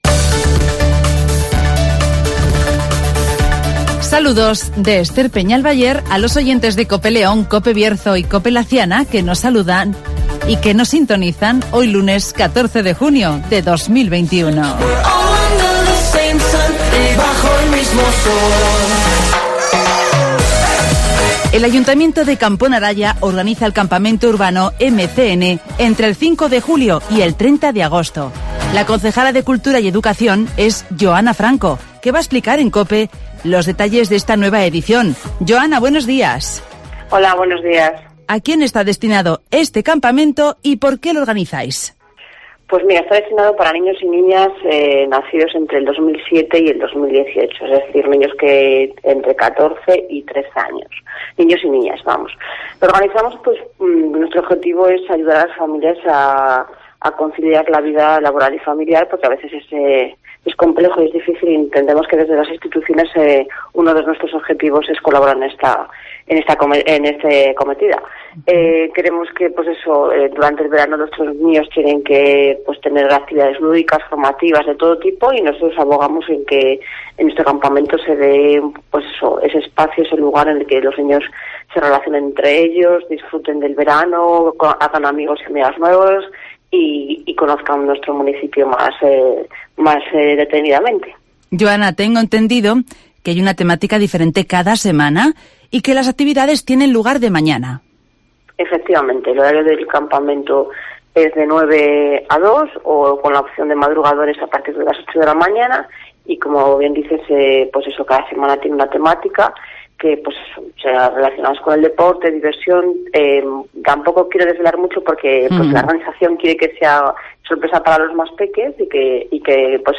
Camponaraya organiza el campamento urbano MCN y anuncia que las piscinas abrirán el 26 de junio (Entrevista